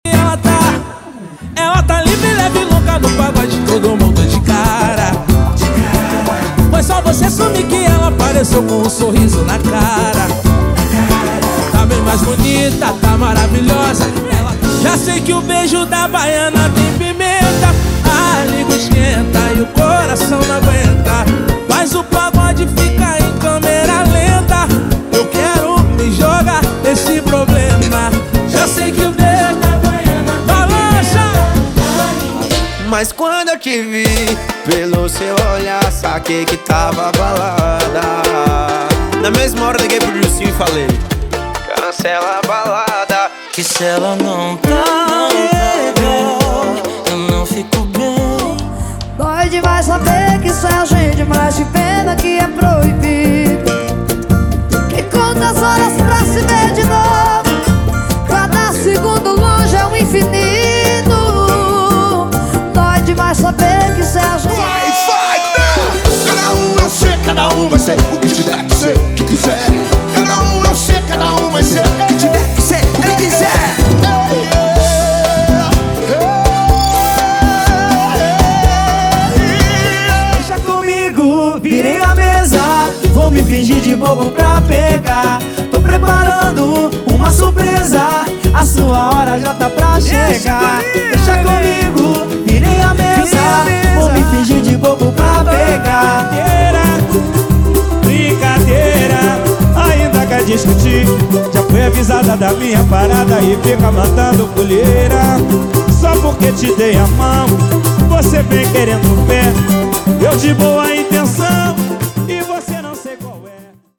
• Pagode e Samba = 30 Músicas